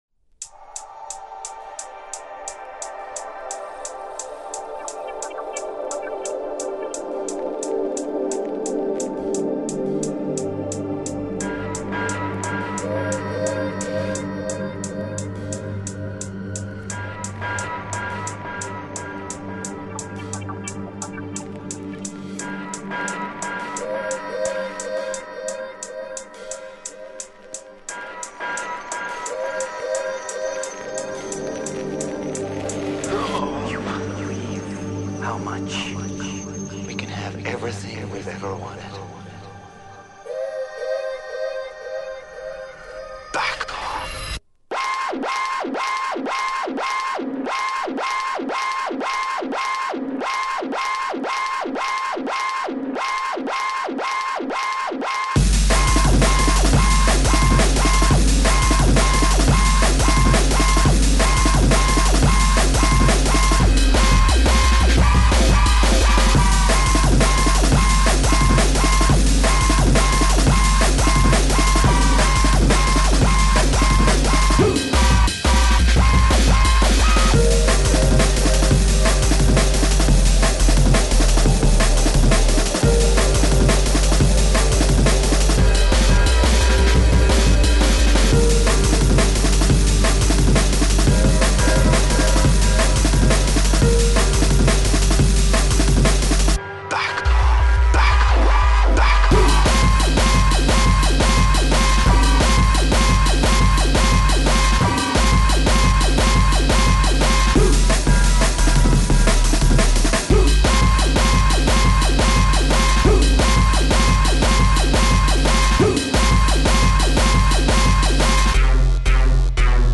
Drum&Bass
DNB
/96kbps) Описание: Любителям дарка качать...